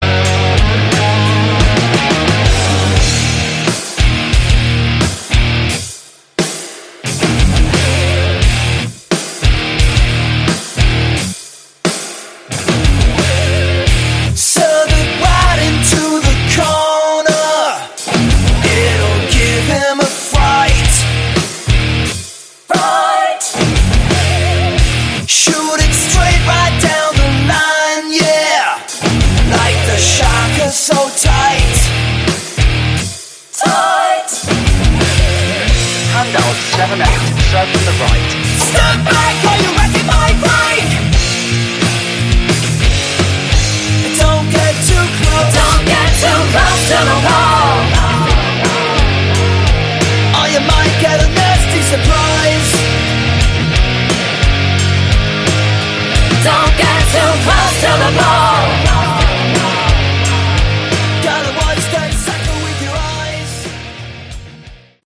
Category: Rock
vocals, guitar
drums, vocals
bass